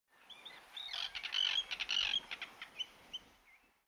دانلود آهنگ پرنده 16 از افکت صوتی انسان و موجودات زنده
دانلود صدای پرنده 16 از ساعد نیوز با لینک مستقیم و کیفیت بالا
جلوه های صوتی